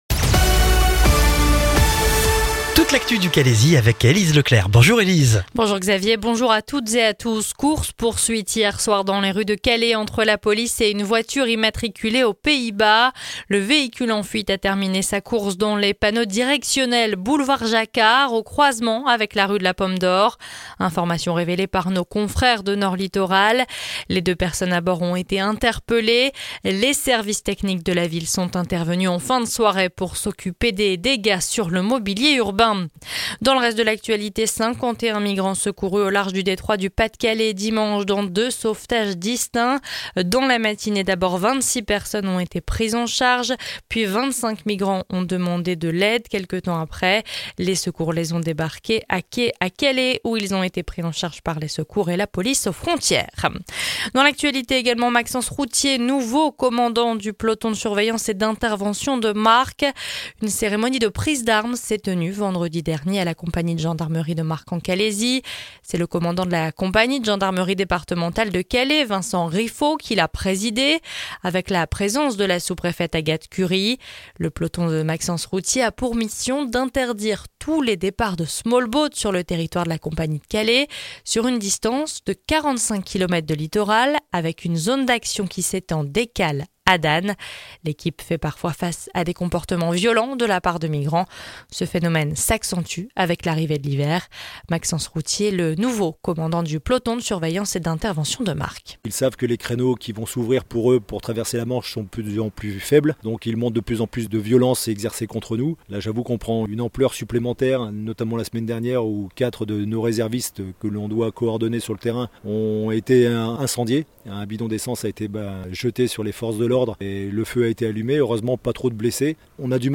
Le journal du mardi 12 novembre dans le Calaisis